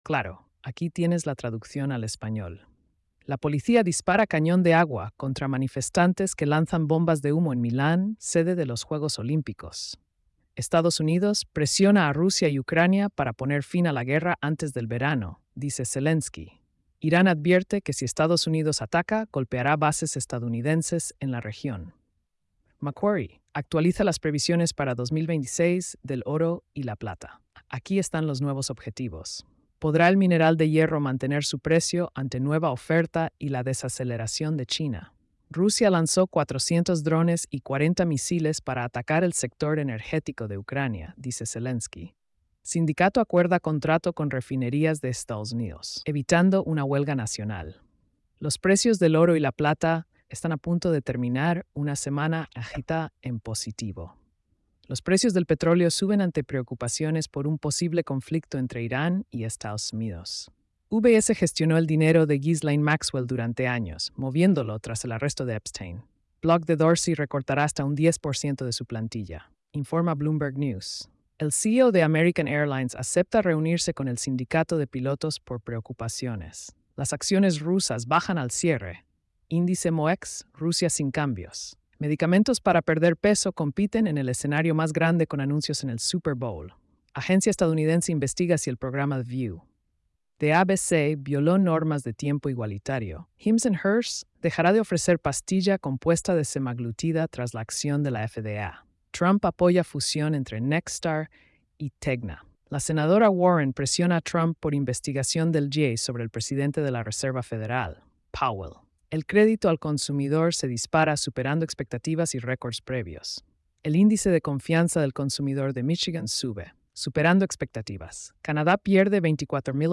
🎧 Resumen Económico y Financiero.